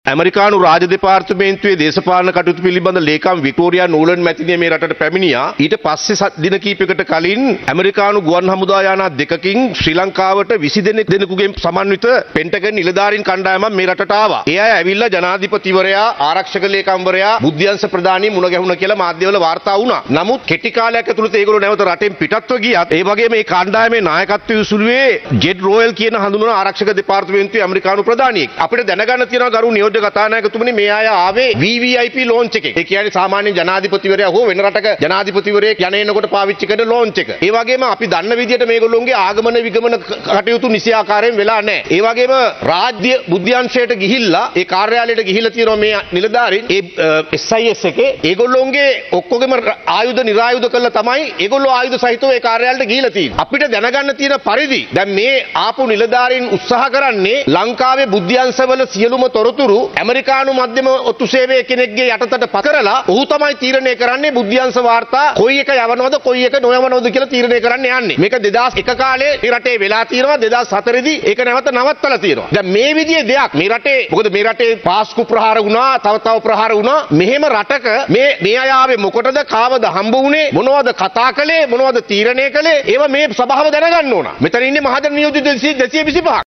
පසුගියදා මෙරටට පැමිණ ඇති ඇමෙරිකානු ආරක්ෂක නිලධාරින් සම්බන්ධයෙන් රජය පැහැදිලි තොරතුරක් ජනතාවට ලබා දී ඇති බවයි අද පාර්ලිමේන්තුවේදී ප්‍රකාශයක් සිදු කරමින් ඔහු සදහන් කළේ.